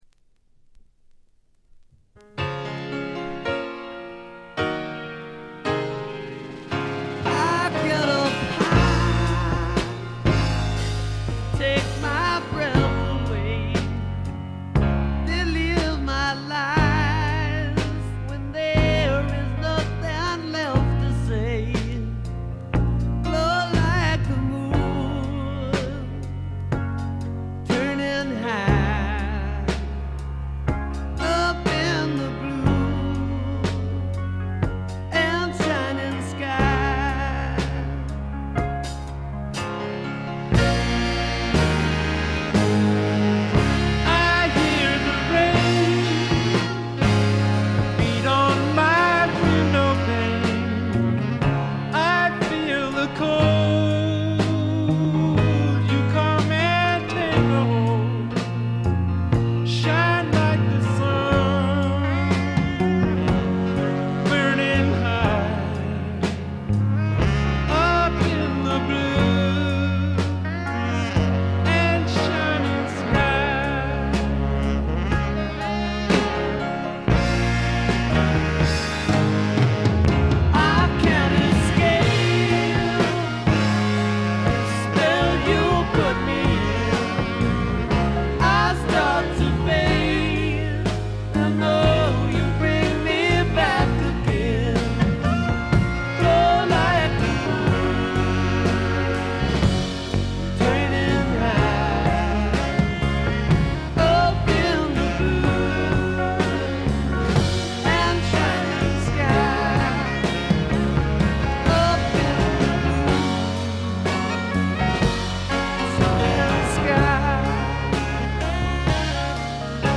ほとんどノイズ感無し。
才気あふれるスワンプサウンドという感じ。
試聴曲は現品からの取り込み音源です。